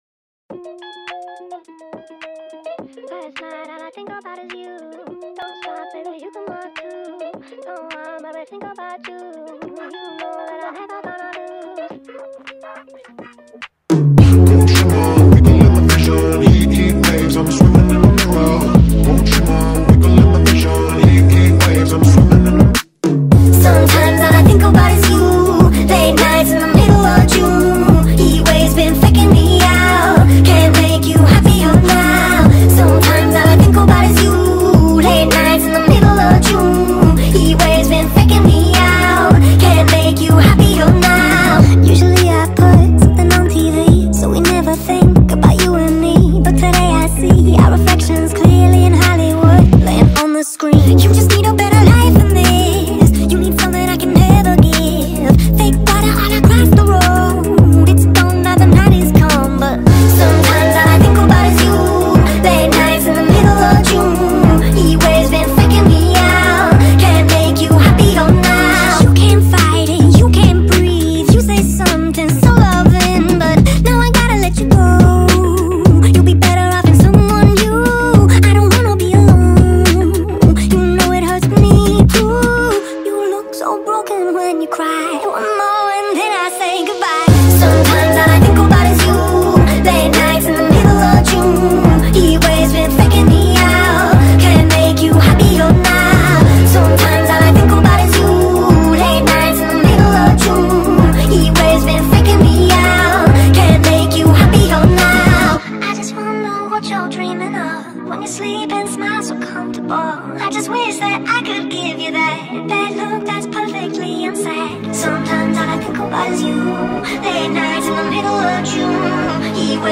شاد
عاشقانه